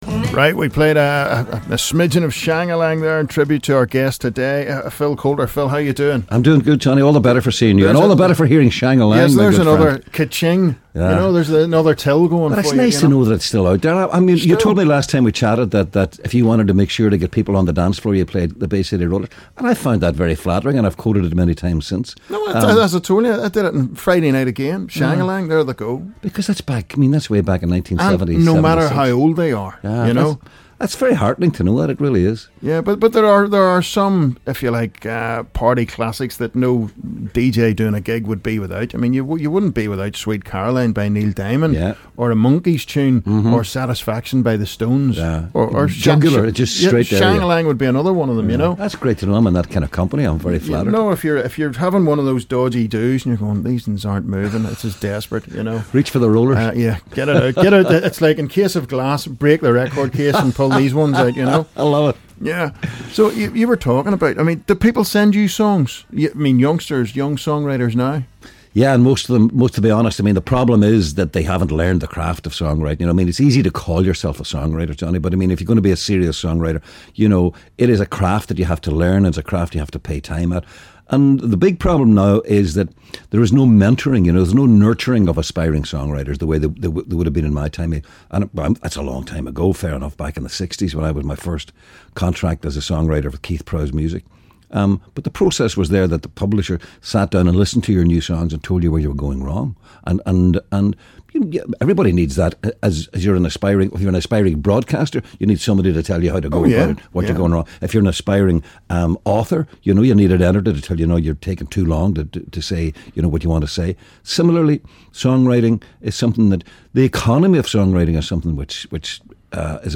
live in the U105 studio!